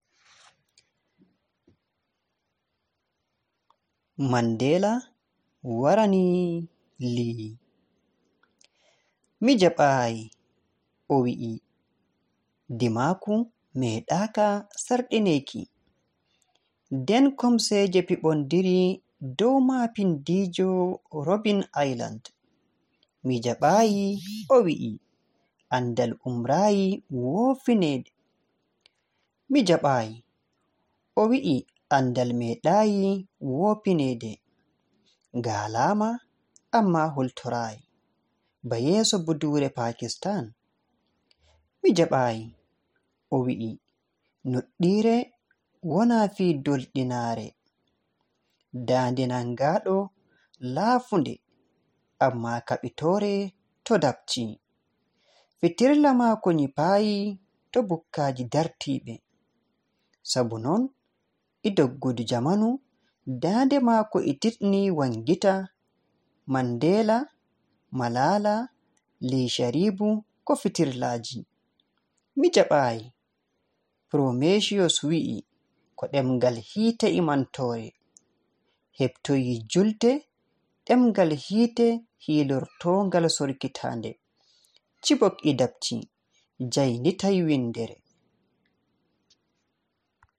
A reading of the poem in Fulfulde